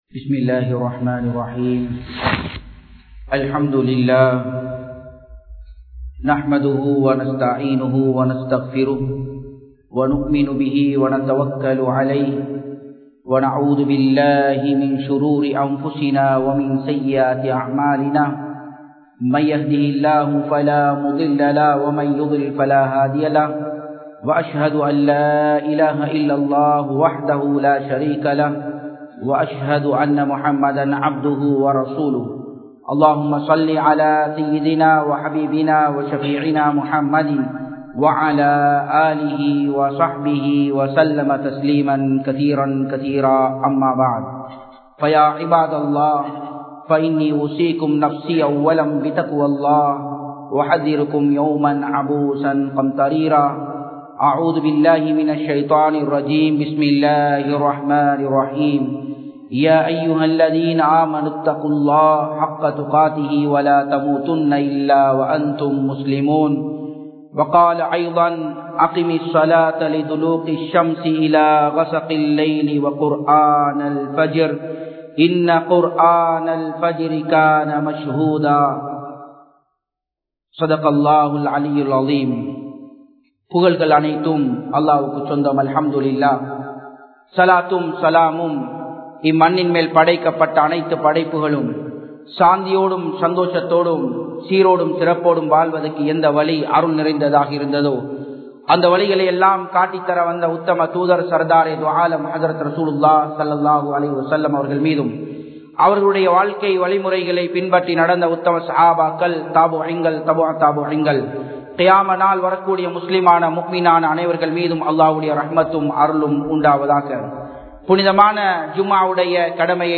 Fajr Tholuhaien Atputhangal (பஜ்ர் தொழுகையின் அற்புதங்கள்) | Audio Bayans | All Ceylon Muslim Youth Community | Addalaichenai
Japan, Nagoya Port Jumua Masjidh 2017-10-20 Tamil Download